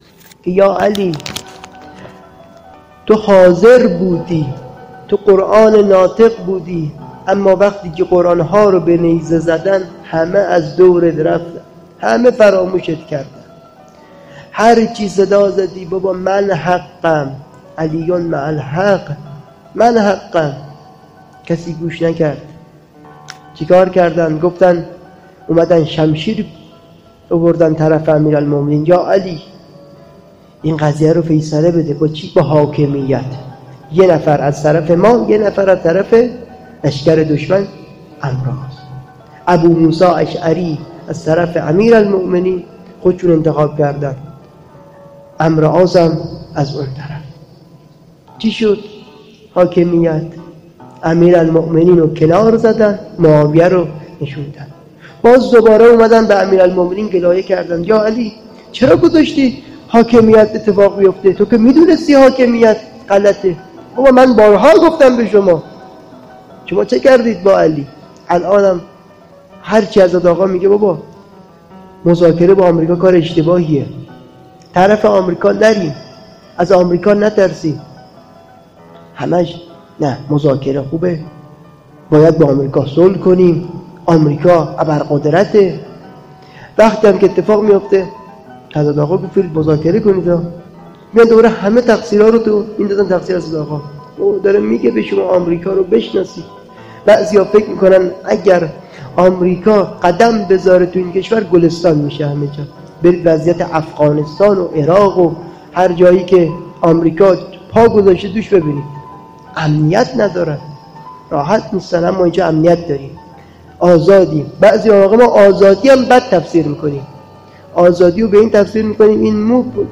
قائم آل محمد راوه - سخنرانی